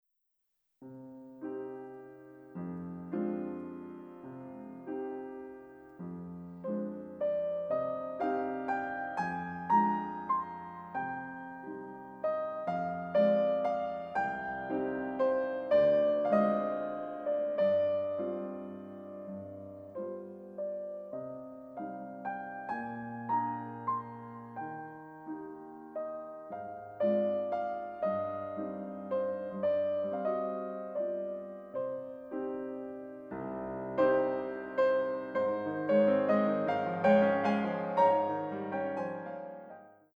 Australian classical music
short piano works